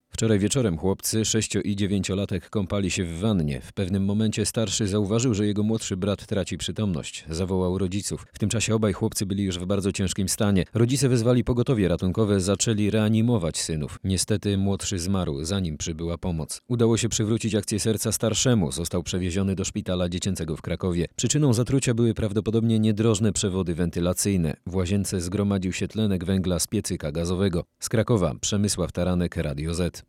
Słuchaj naszego reportera Tlenek węgla nie ma ani zapachu ani barwy.